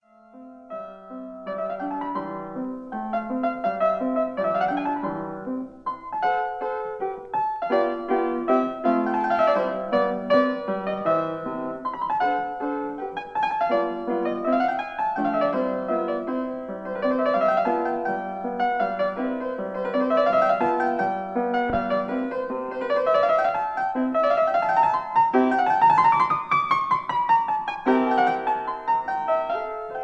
fortepiano
Allegro Rondo - Allegretto